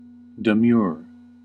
Ääntäminen
France: IPA: [dis.kʁɛ]